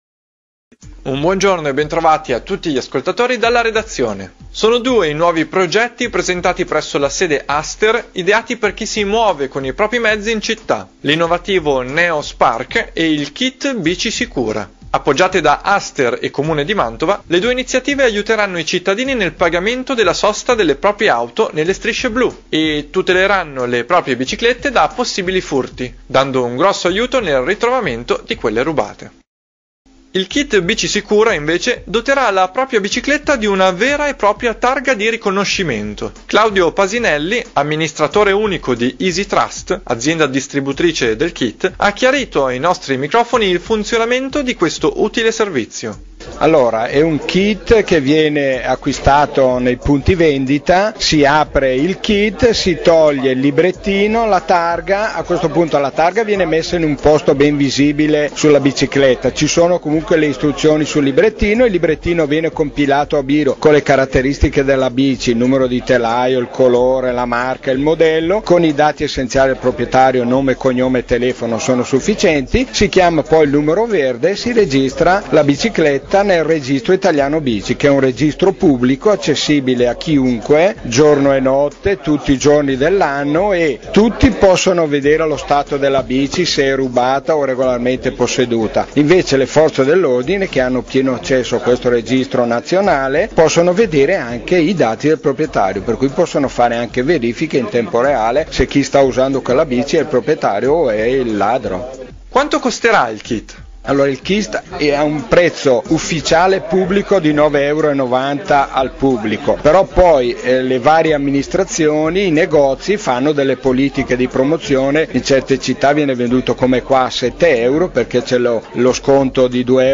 MN_intervista_CP.wma